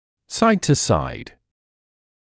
[saɪd-tə-saɪd][сайд-тэ-сайд]из стороны в сторону